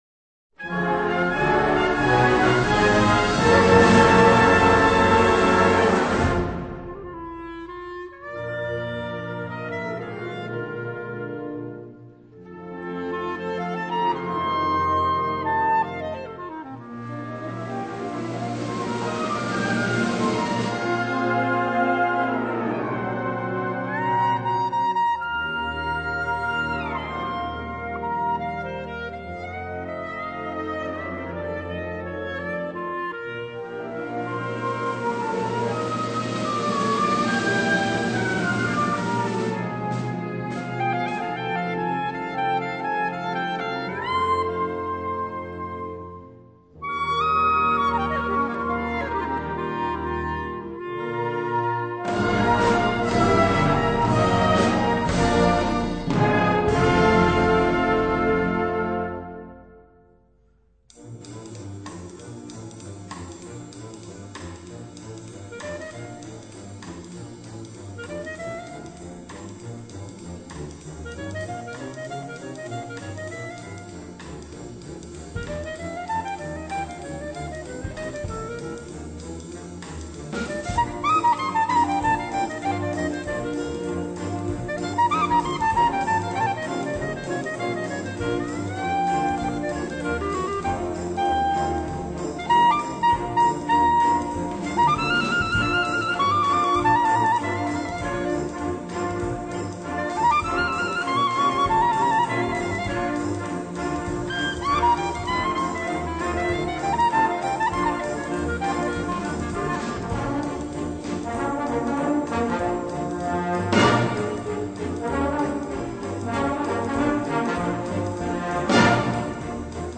Solo für Klarinette
Blasorchester